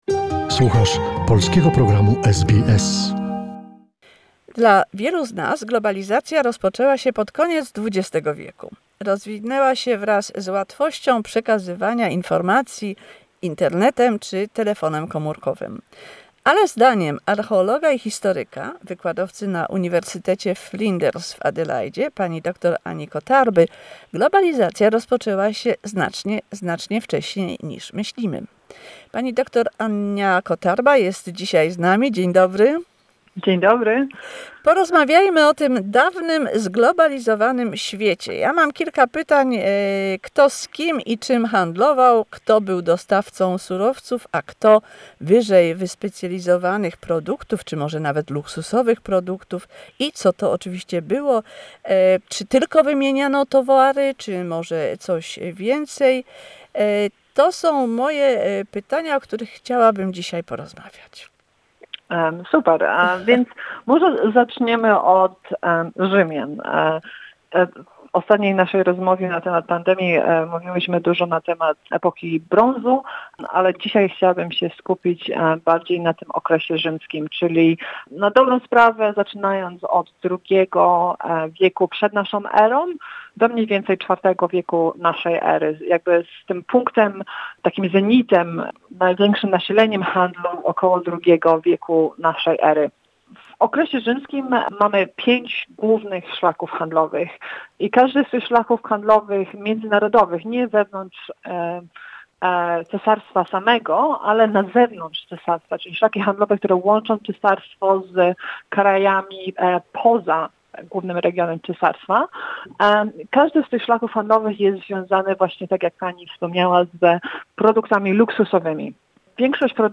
What were the routes? Who and what were traded with? The 4 parts conversation